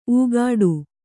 ♪ ūgāḍu